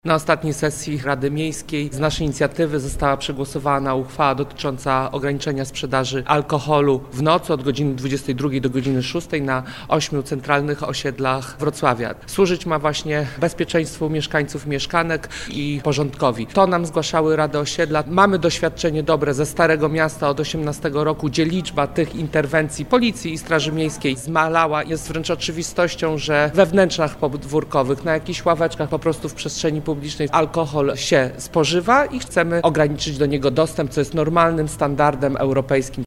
-Chodzi nam o bezpieczeństwo mieszkanek i mieszkańców Wrocławia. Ograniczenie dostępności alkoholu jest europejską normą. – mówi Bartłomiej Ciążyński, wiceprezydent Wrocławia.